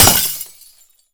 glass_smashable_small_break_02.wav